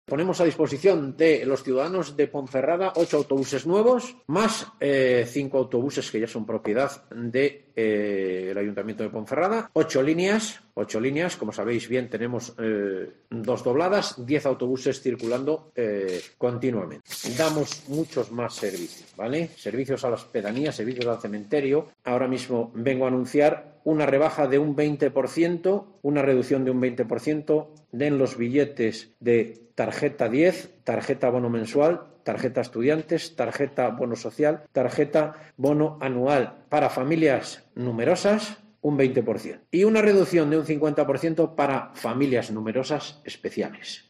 AUDIO: Escucha aquí las palabras del concejal de Seguridad Ciudadana y Movilidad de la capital berciana, José Antonio Cartón